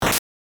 刺突02 - 音アリー